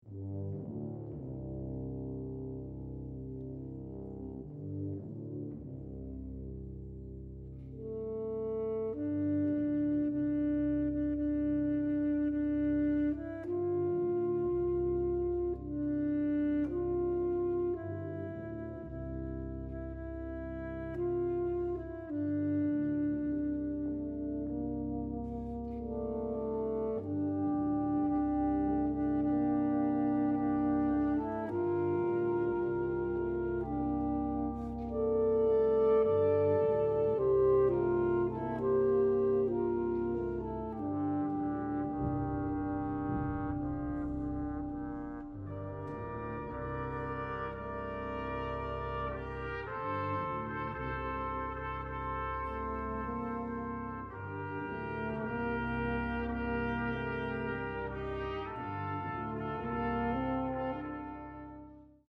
is a traditional Welsh folk song.